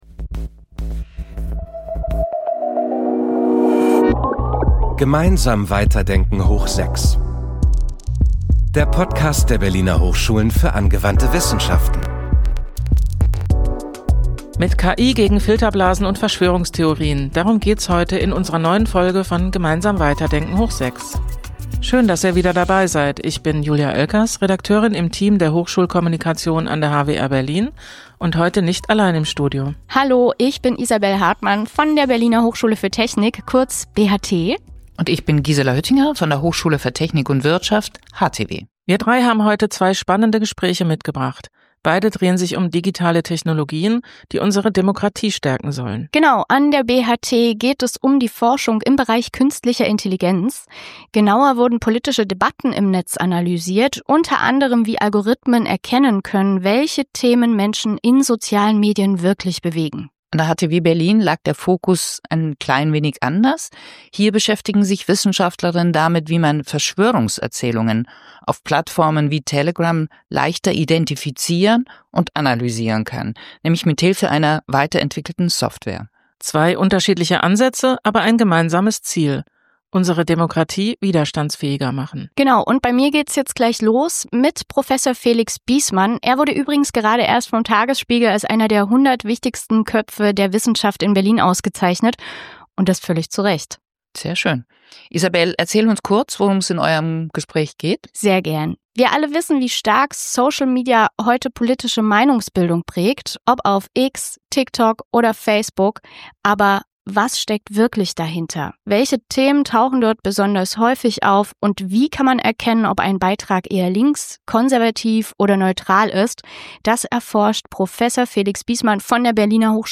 Im Gespräch: